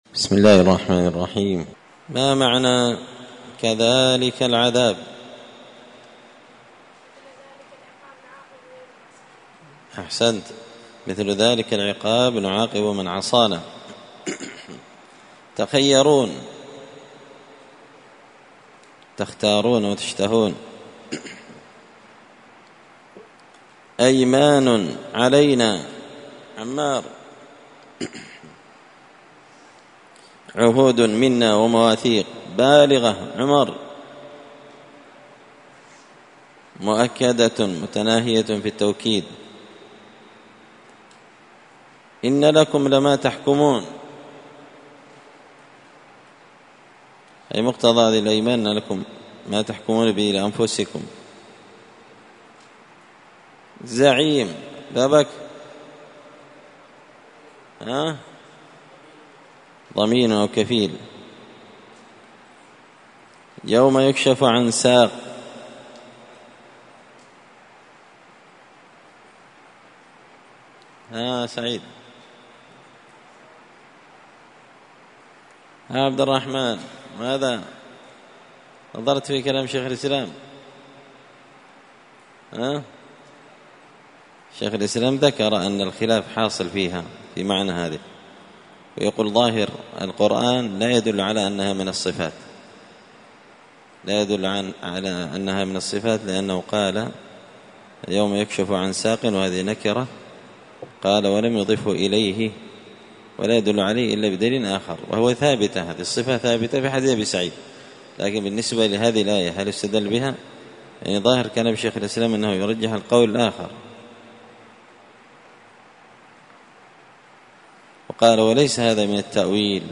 66الدرس-السادس-والستون-من-كتاب-زبدة-الأقوال-في-غريب-كلام-المتعال.mp3